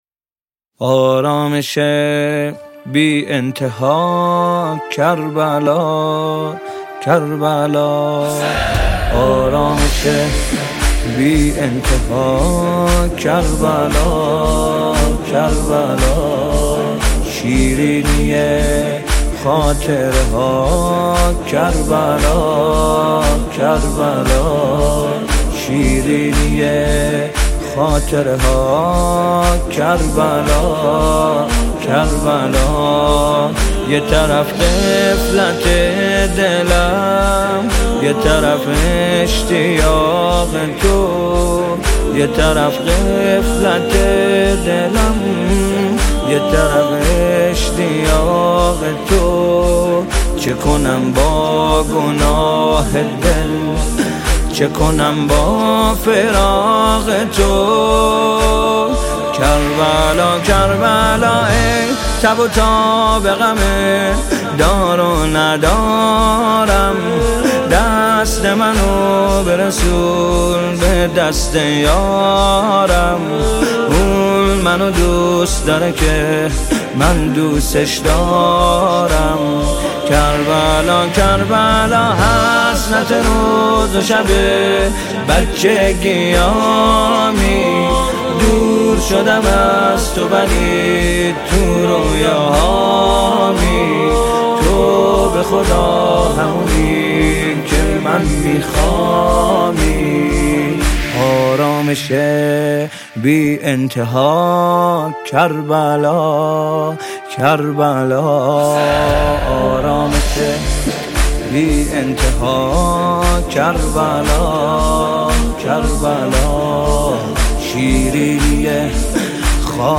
مداحی امام حسین مناجات با امام حسین